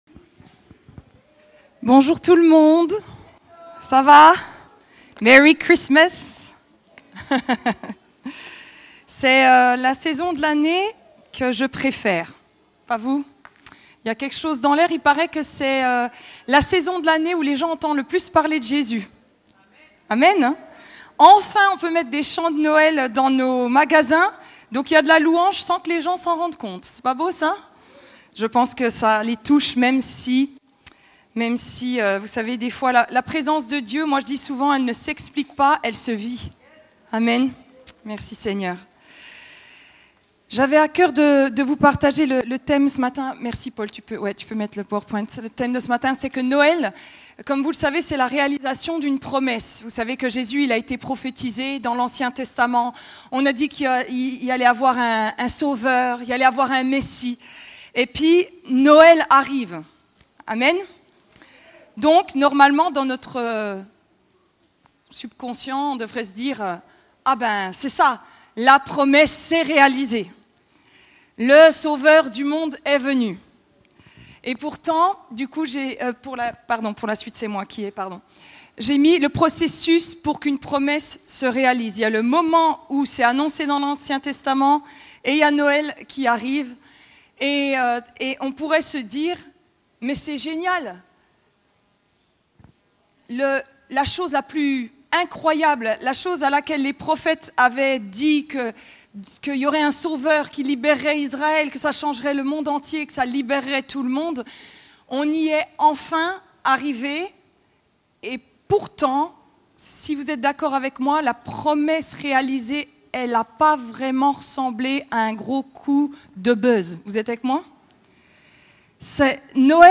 Type De Service: Prédication